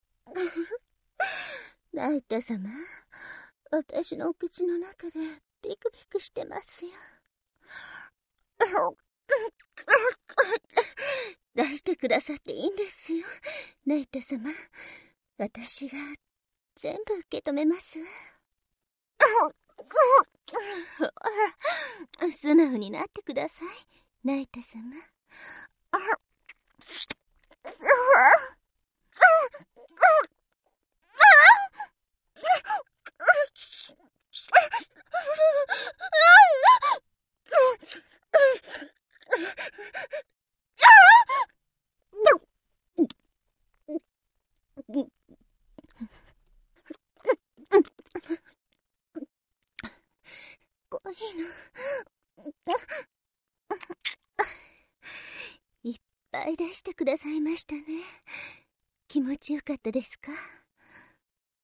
就是ミ-ウ那个环节里面女仆装的那个 声音很御姐呢 有爱